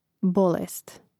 bȍlēst bolest